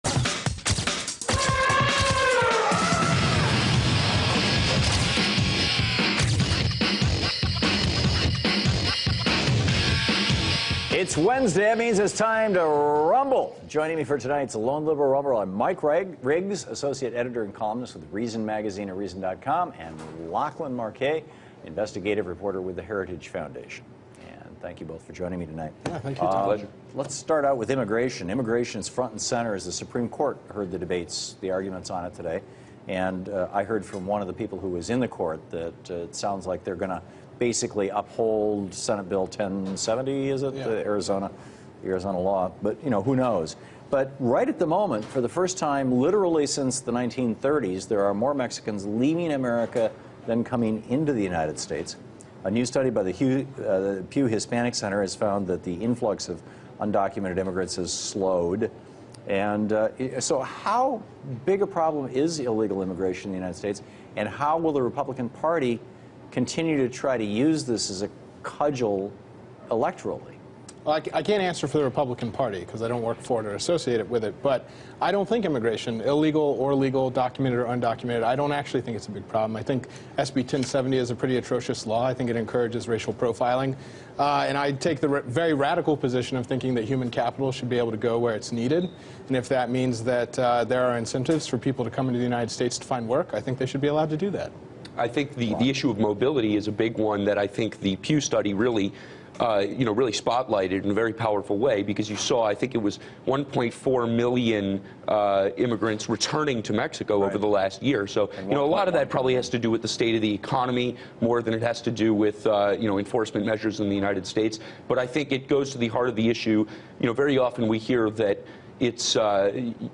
The panel also discusses whether austerity and cutting spending have lead European nations into another recession.